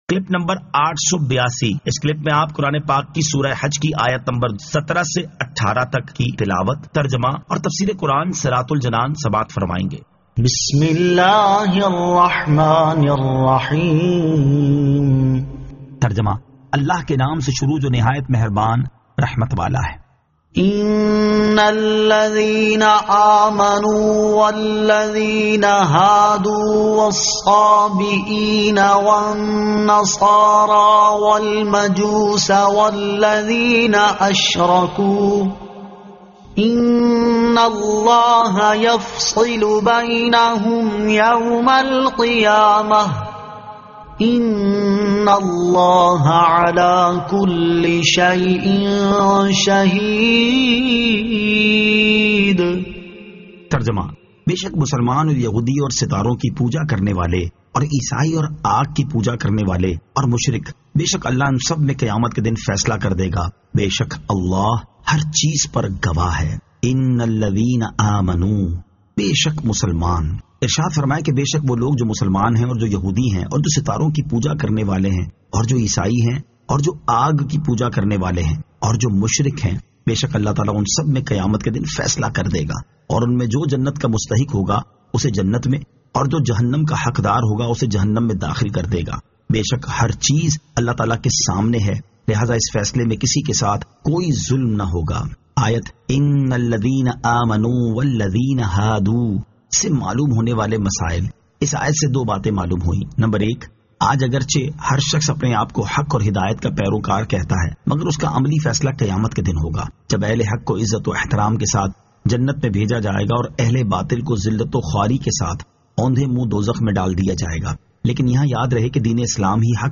Surah Al-Hajj 17 To 18 Tilawat , Tarjama , Tafseer